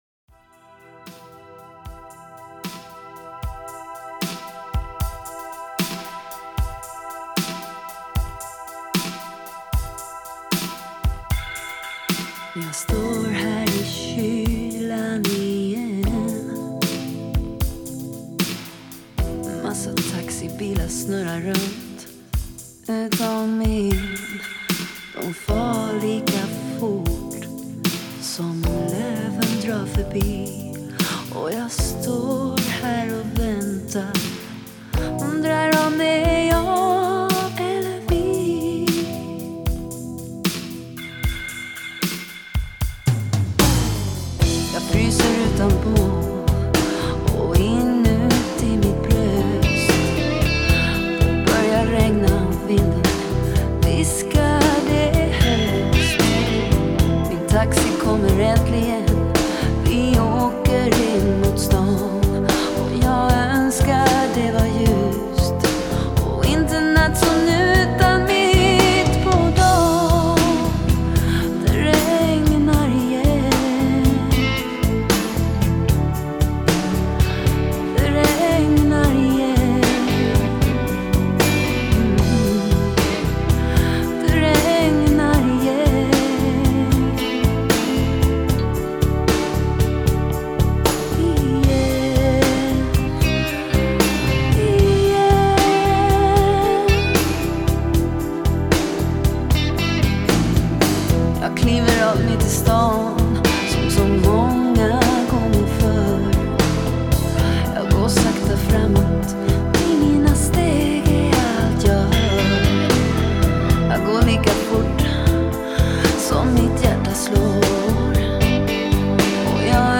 Жанр: Pop Rock Страна: Sweden